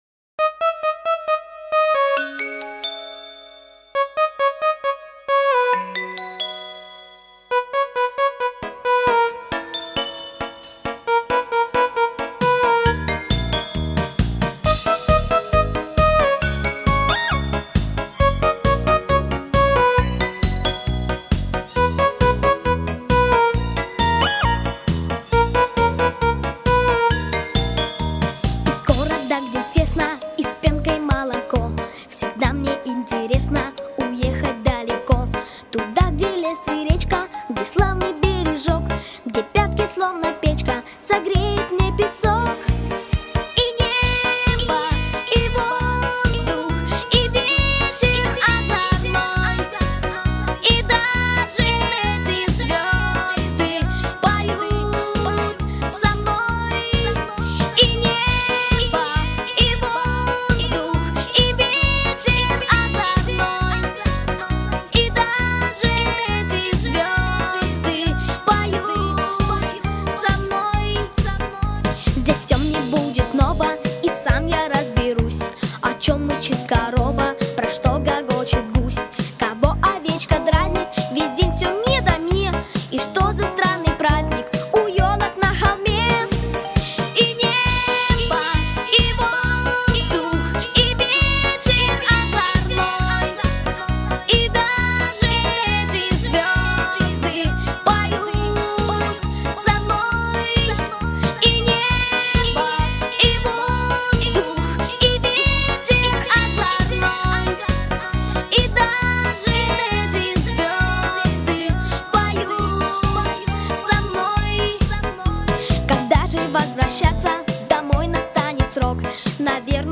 ГлавнаяПесниПесни про лето